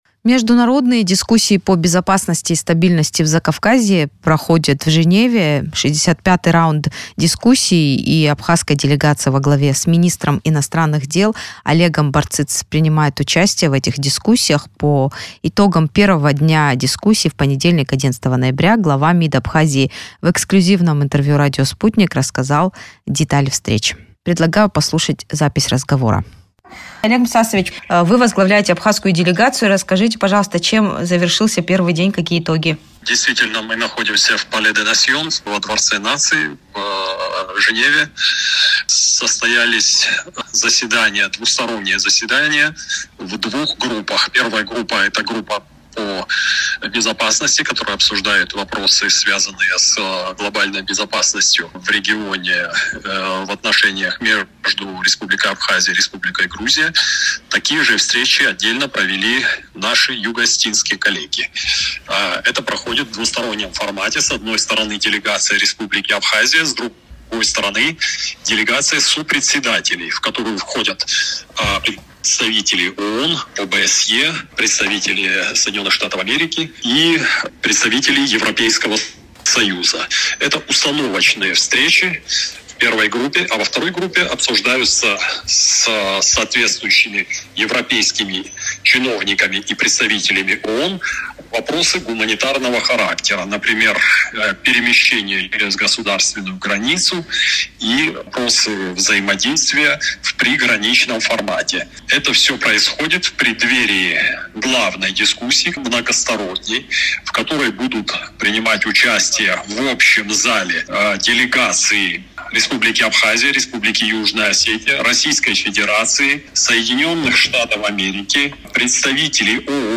Женевские дискуссии: эксклюзив с места события с главой МИД Абхазии
Олег Барциц, министр иностранных дел Абхазии
Абхазия по-прежнему настаивает на подписании со стороны Грузии юридически обязывающего соглашения о неприменении силы, сообщил в эксклюзивном комментарии Sputnik глава МИД Олег Барциц. Он находится в Женеве, где проходит очередной раунд дискуссий по безопасности и стабильности в Закавказье.